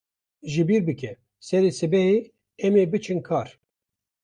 Pronounced as (IPA) /biːɾ/